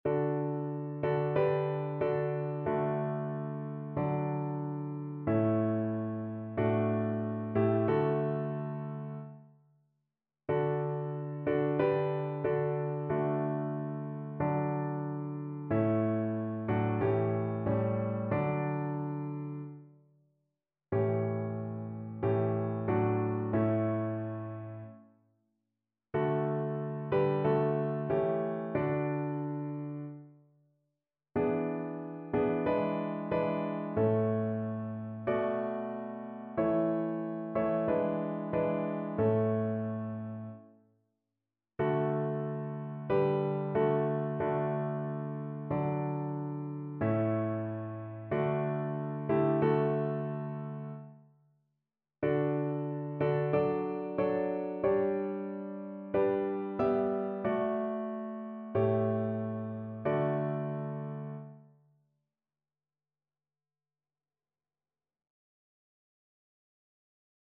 Notensatz 1 (4 Stimmen gemischt)
• gemischter Chor [MP3] 1 MB Download